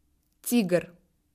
Consonnes
Écoutez la différence en russe sur ces quelques exemples:
"MOU"